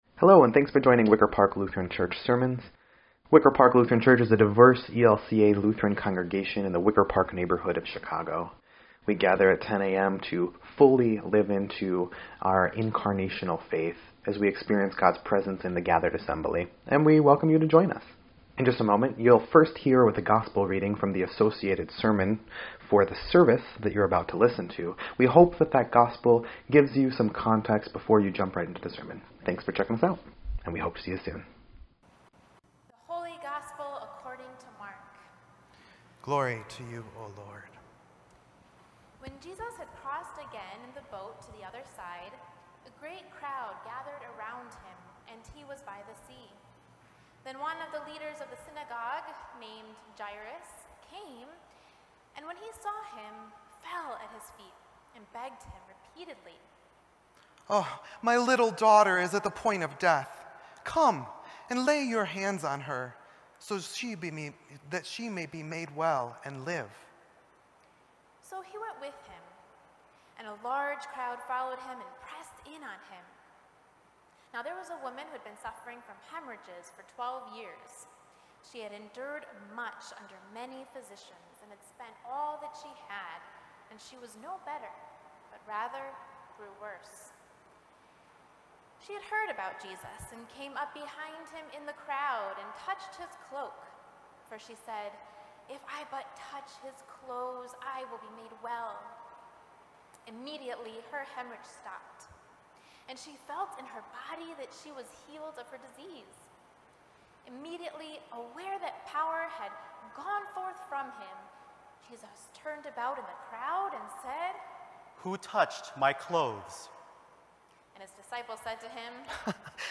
6.27.21-Sermon_EDIT.mp3